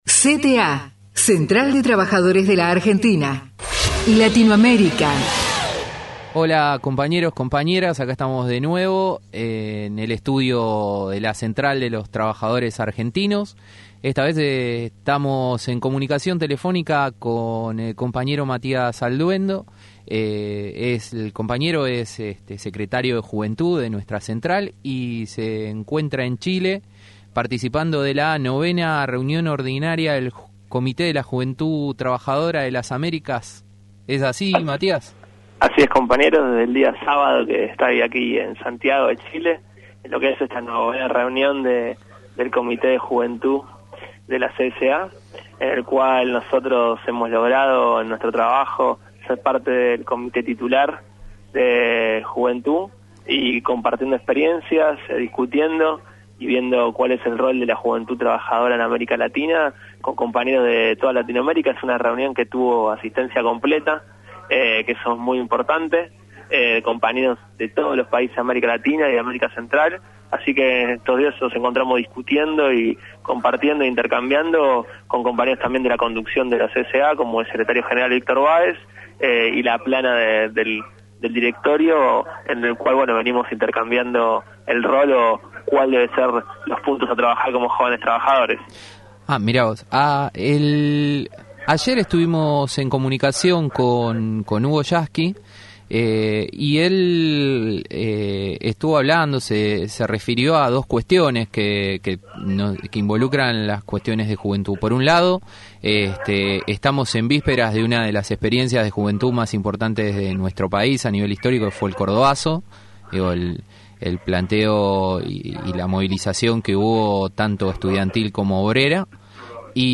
ENTREVISTA RADIO CENTRAL - Desde Santiago, Chile - 9ª Reunión Ordinaria del Comité de la Juventud Trabajadora de las Américas